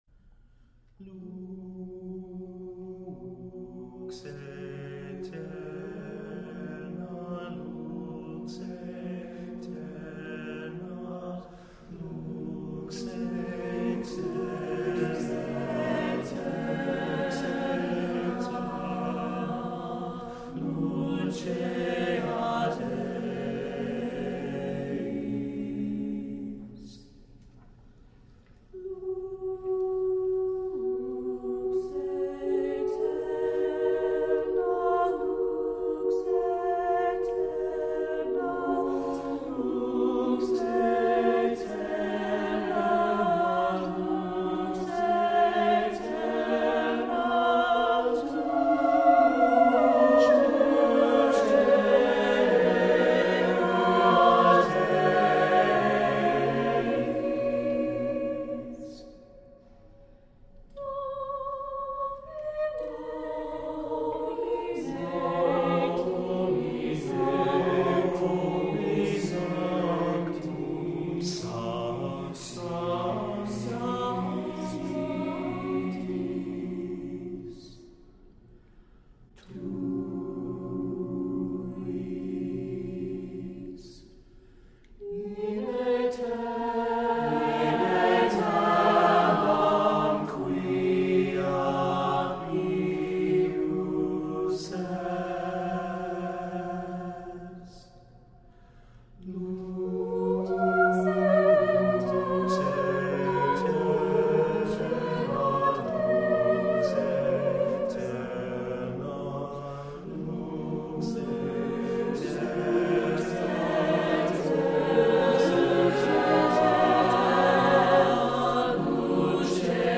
Lux Aeterna (SSAATTBB Choir)